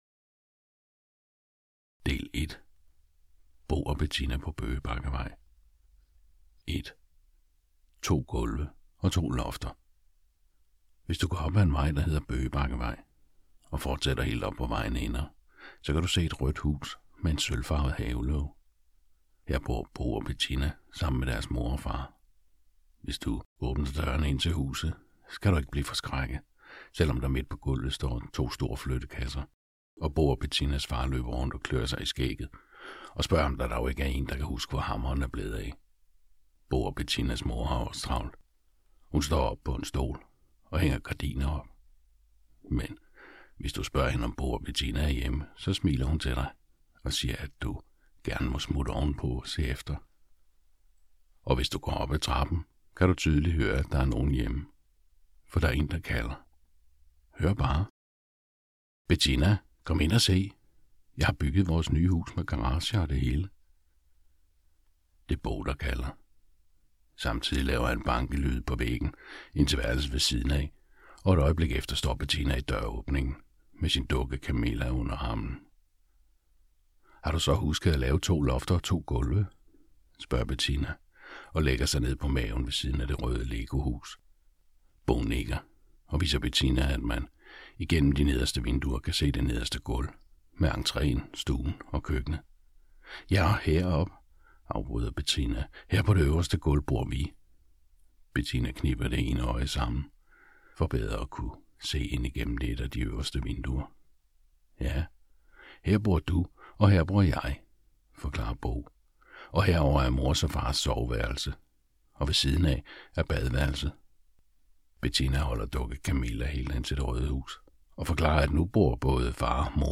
Hør et uddrag af Bo og Betinna Bo og Betinna Børneroman i fire dele Format MP3 Forfatter Bodil Sangill Bog Lydbog 99,95 kr.